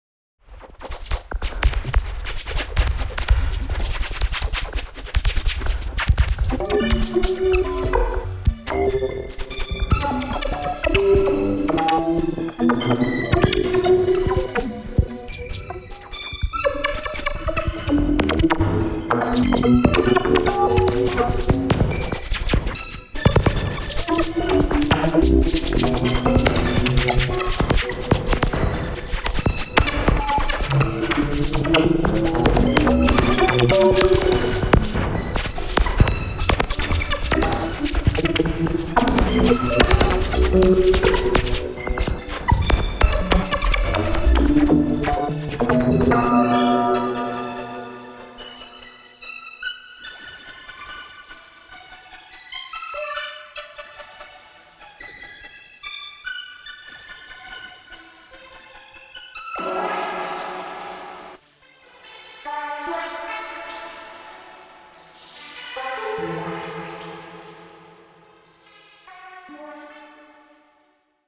Nord Lead A1 solo